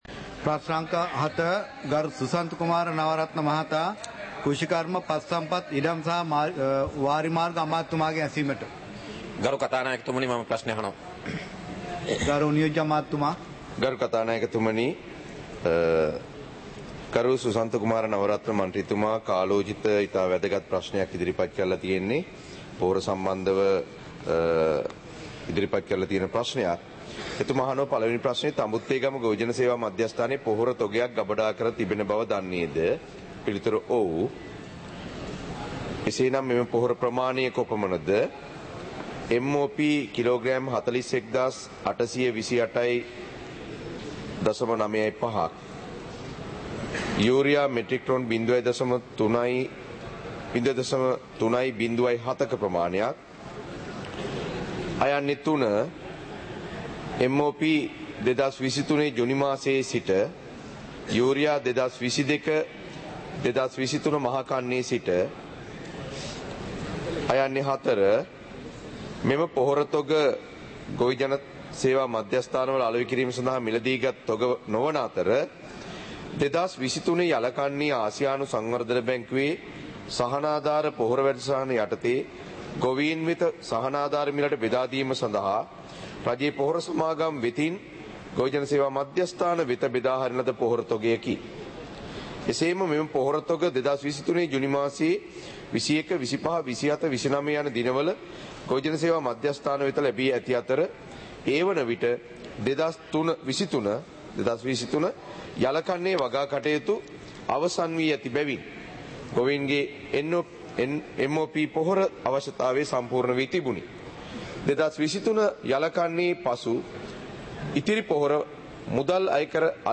நேரலை - பதிவுருத்தப்பட்ட